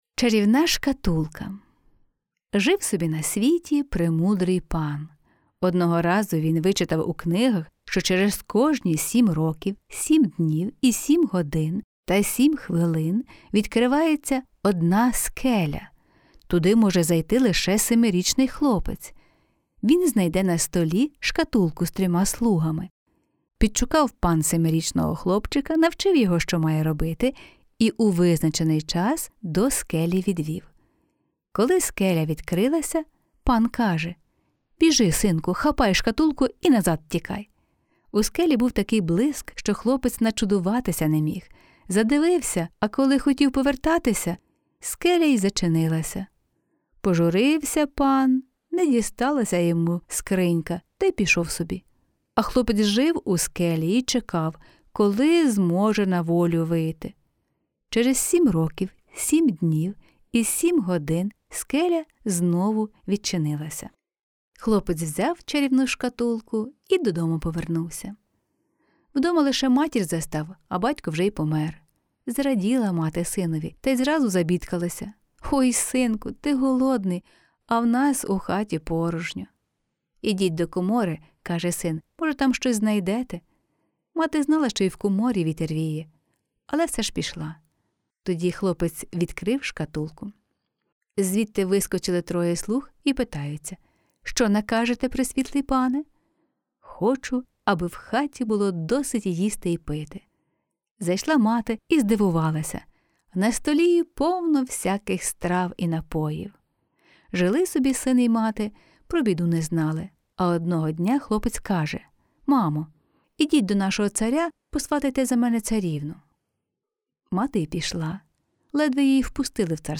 Жанр книги: Казка на добраніч.